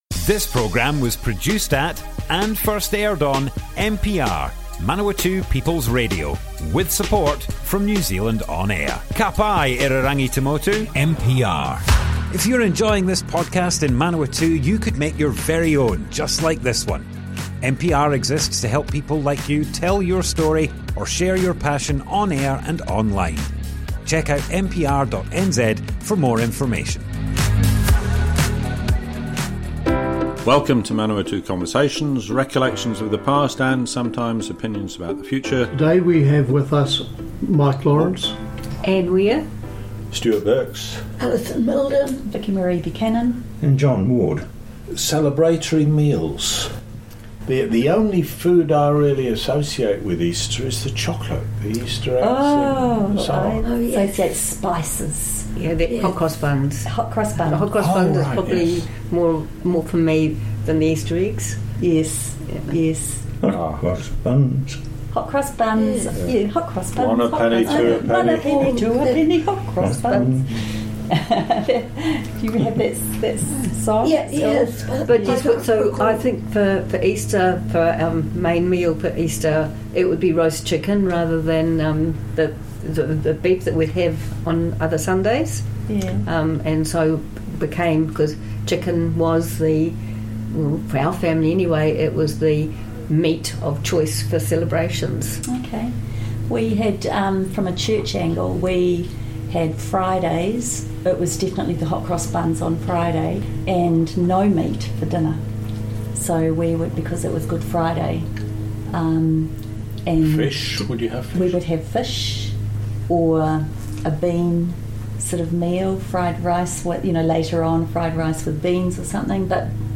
Group discussion, part 2, Easter and Christmas - Manawatu Conversations - Manawatū Heritage
00:00 of 00:00 Add to a set Other Sets Description Comments Group discussion, part 2, Easter and Christmas - Manawatu Conversations More Info → Description Broadcast on Manawatu People's Radio, 26th March 2024.
oral history